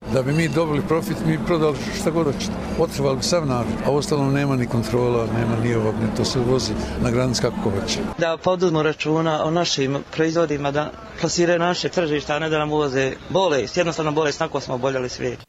Anketa